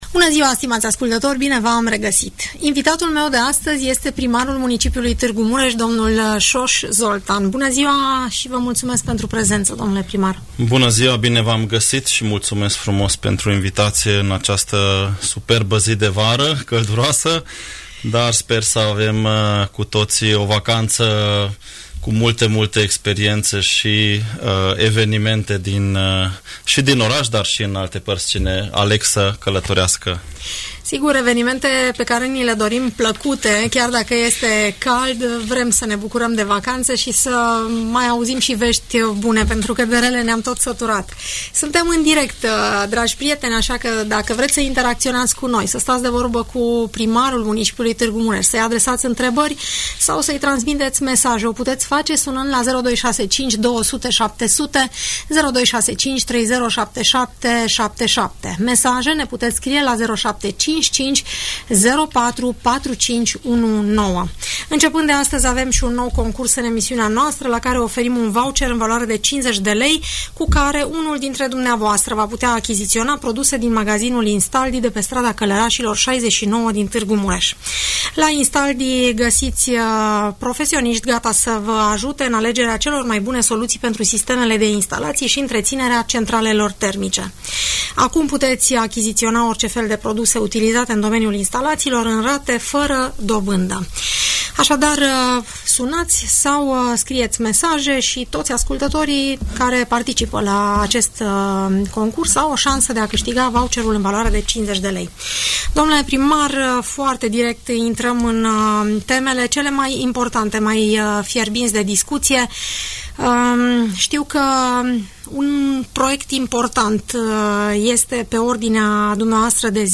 Primarul Soós Zoltán, în audiență la Radio Tg.Mureș